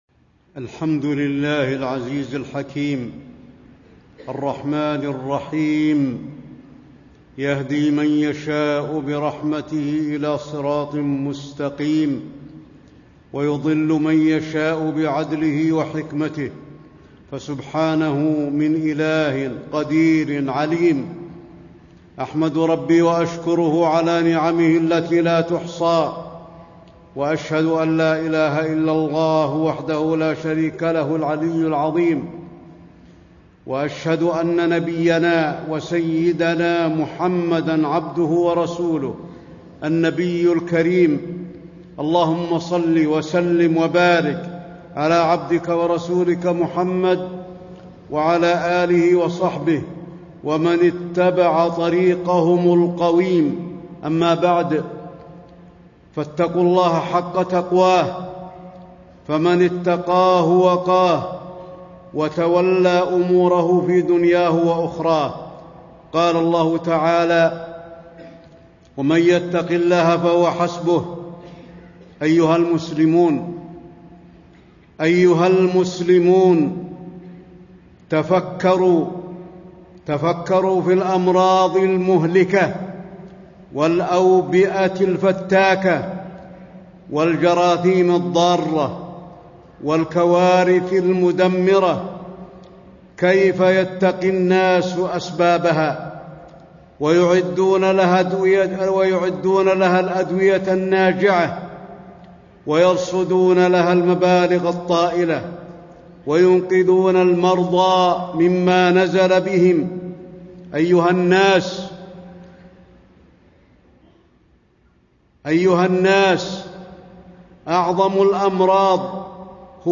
تاريخ النشر ١٩ شوال ١٤٣٥ هـ المكان: المسجد النبوي الشيخ: فضيلة الشيخ د. علي بن عبدالرحمن الحذيفي فضيلة الشيخ د. علي بن عبدالرحمن الحذيفي النفاق وأنواعه